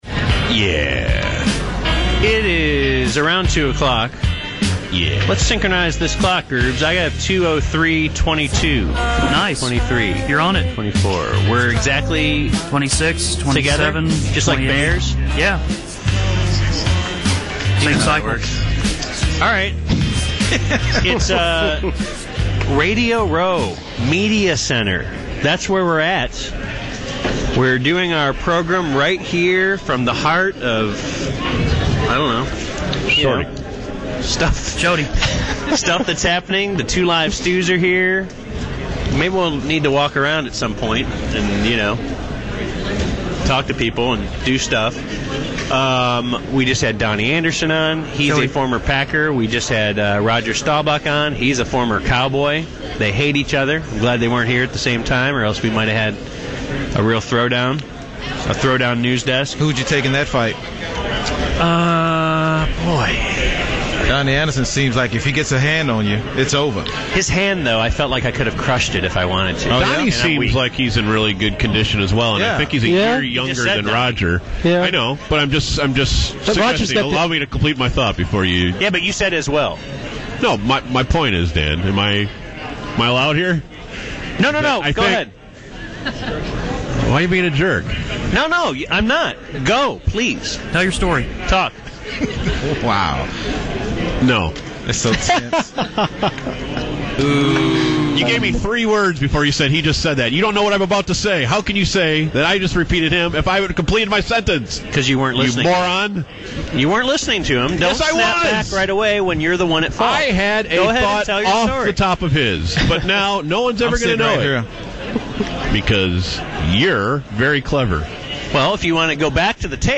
. BaD Radio got off to a rocky start